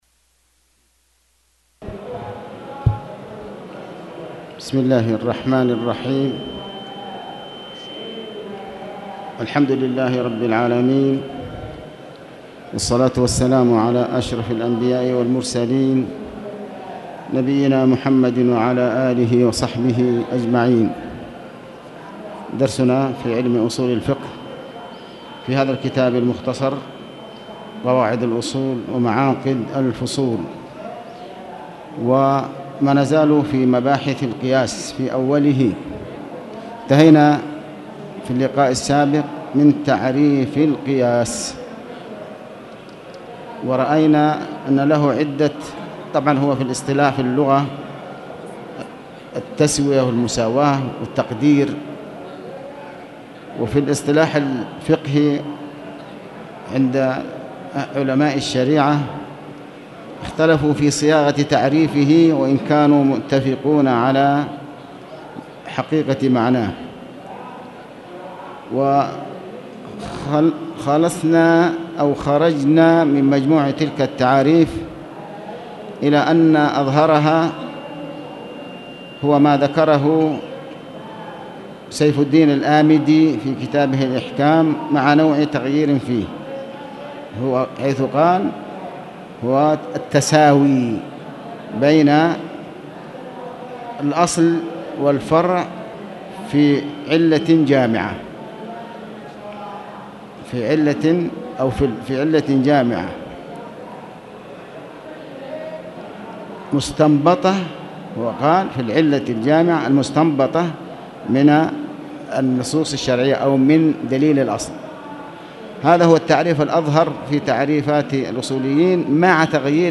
تاريخ النشر ٤ محرم ١٤٣٨ هـ المكان: المسجد الحرام الشيخ